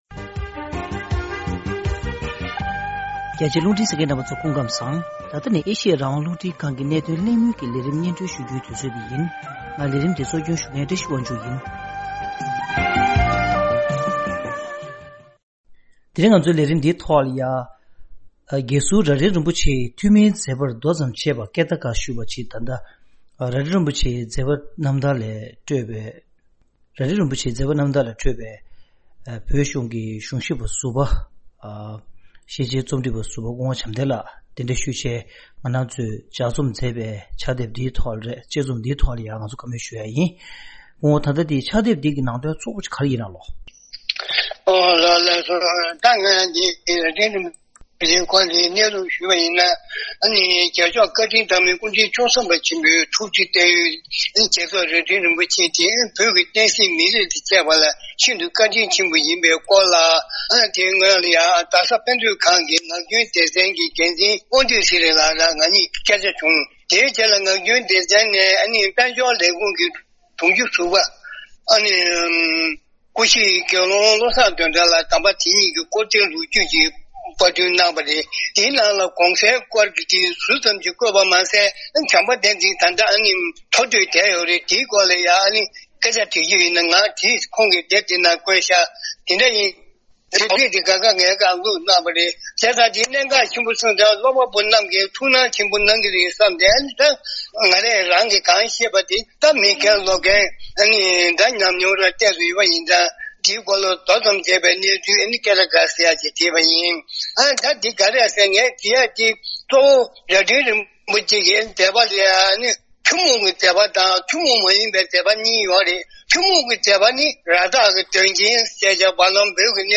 ༄༅།།ཐེངས་འདིའི་གནད་དོན་གླེང་མོལ་གྱི་ལས་རིམ་ནང་།